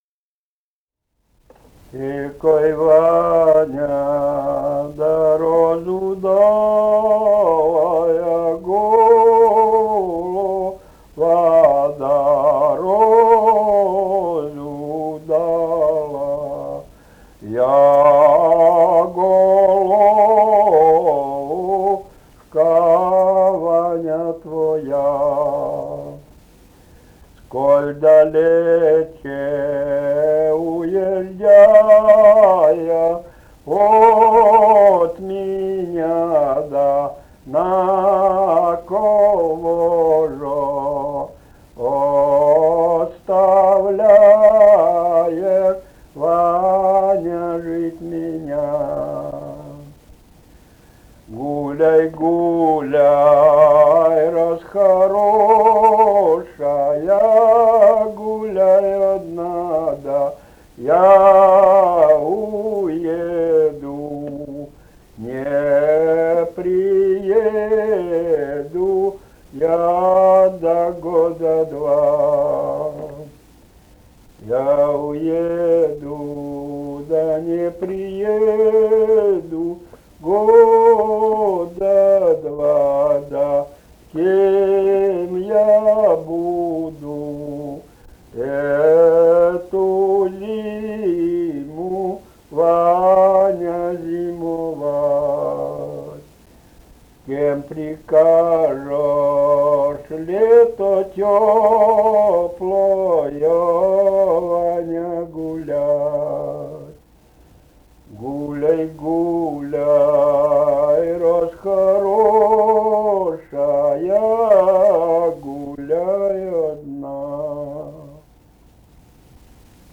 полевые материалы
Вологодская область, д. Осподаревская Тигинского с/с Вожегодского района, 1969 г. И1130-29